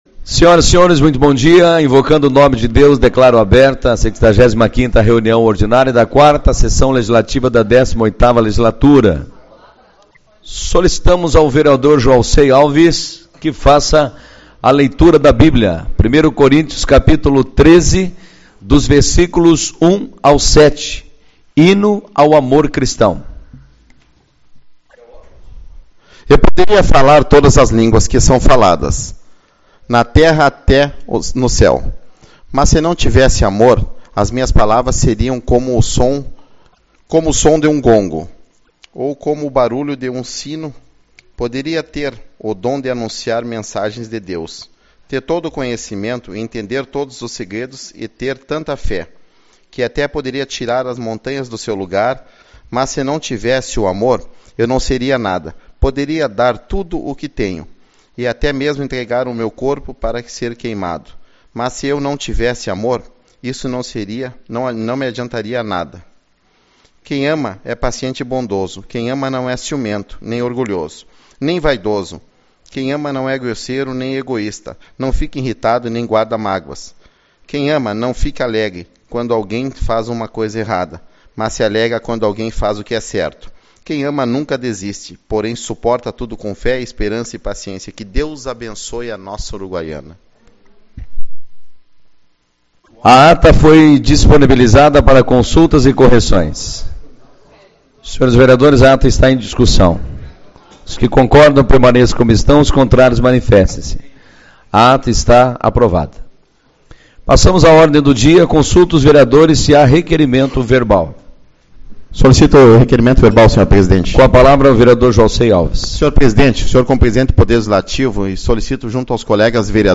08/10 - Reunião Ordinária